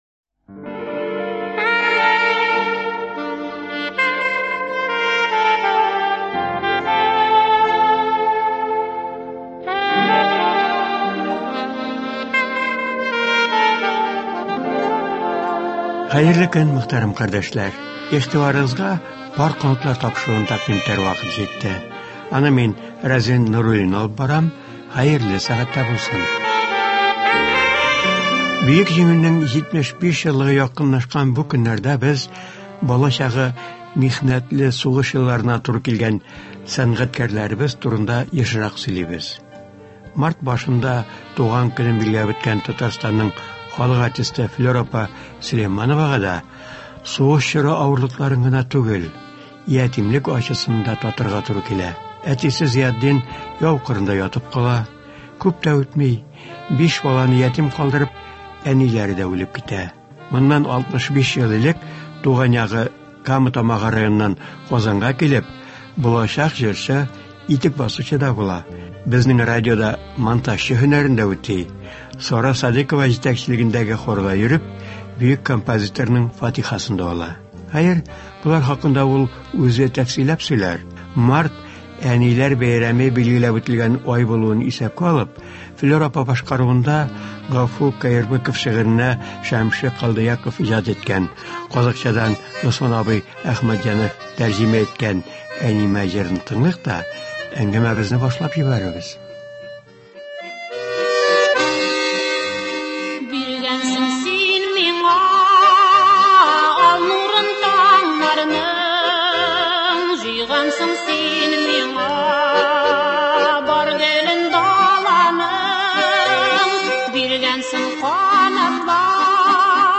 Ул ятимлек ачысы, бала чагында күргән авырлыклар, иҗатка ничек килүе турында сөйли. Тапшыруда шулай ук аның башкаруында җырлар яңгырый.